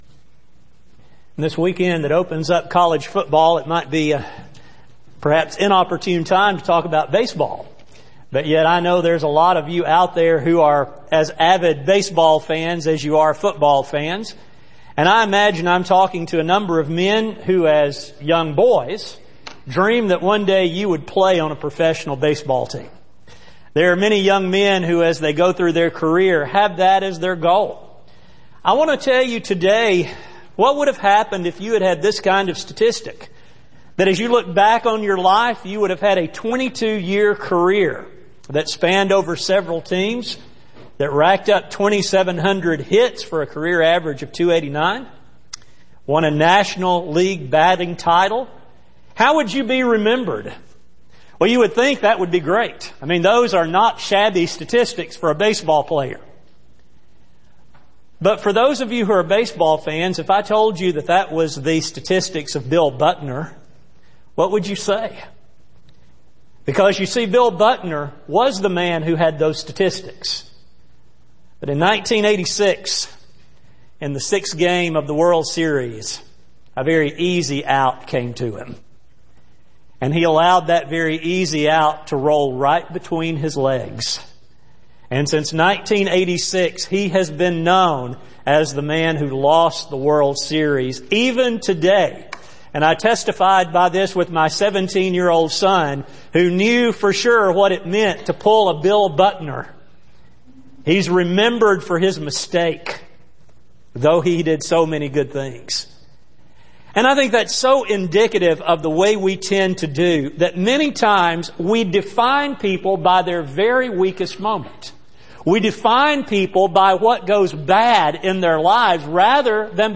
Series: The Apostles Service: Sun AM Type: Sermon